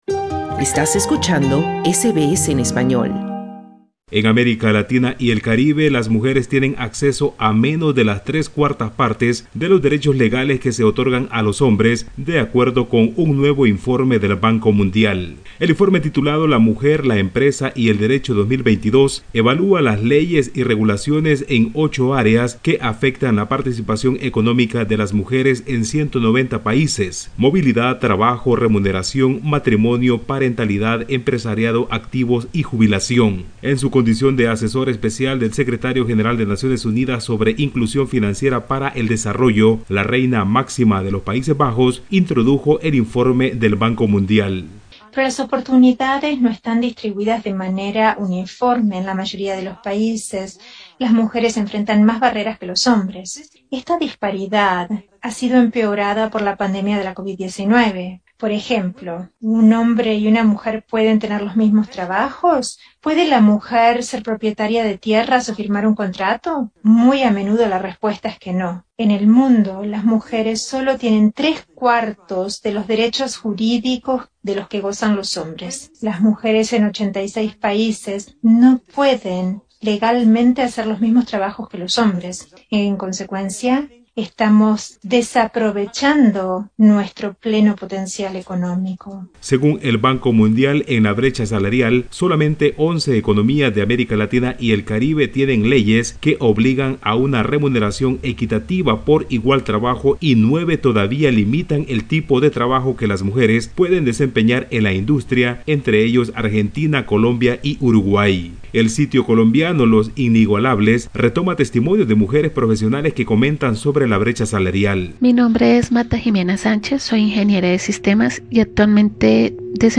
Escucha el informe del…